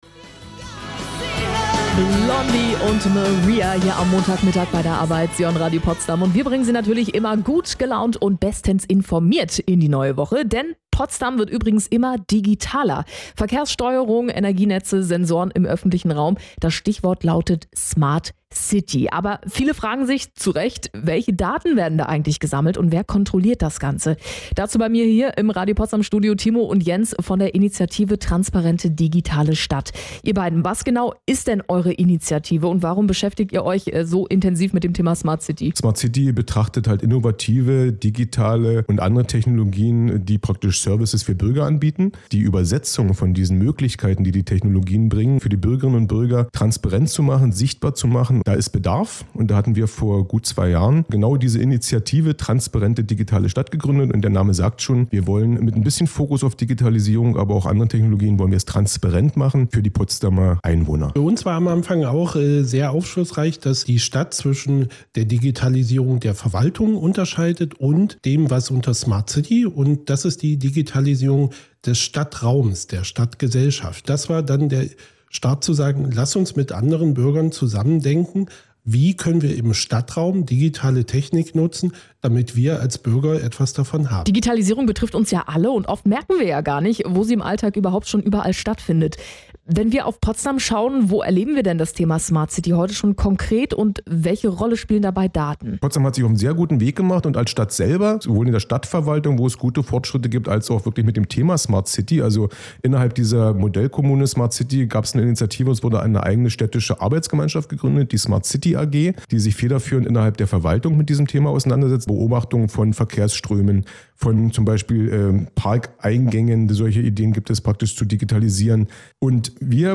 Radio Potsdam – Interview für die nächste Veranstaltung – Initiative Transparente Digitale Stadt
In entspannter, zugleich konzentrierter Atmosphäre konnten wir unseren Verein vorstellen und Einblicke in unsere Arbeit rund um Smart City geben.